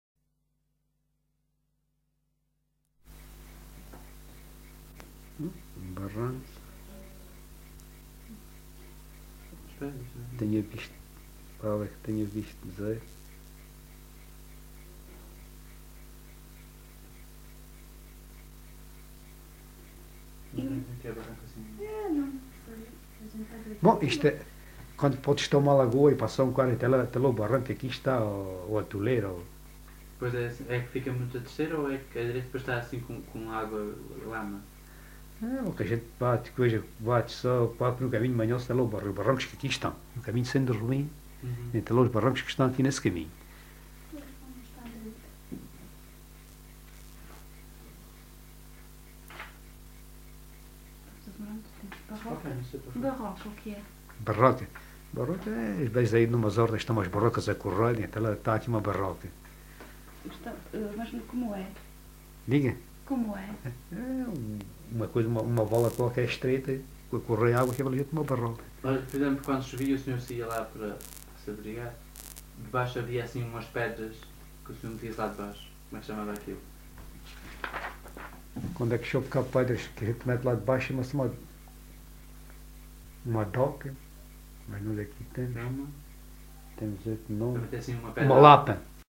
LocalidadeNisa (Nisa, Portalegre)